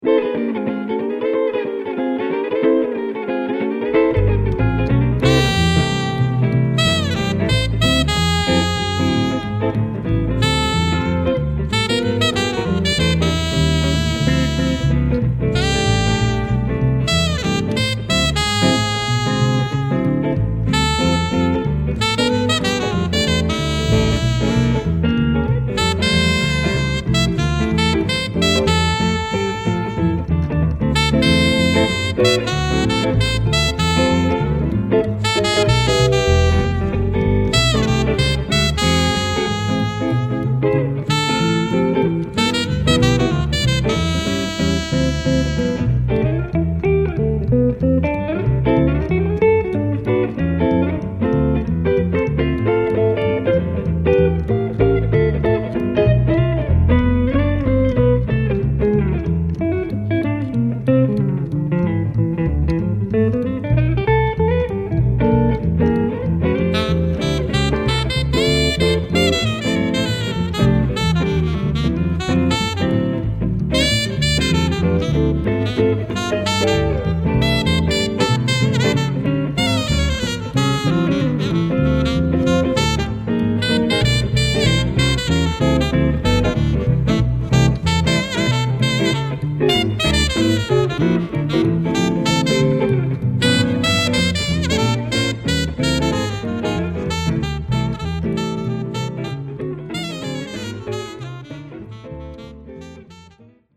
JAZZ GUITAR
Jazz Trio of Guitar, Flute/Sax and Upright Bass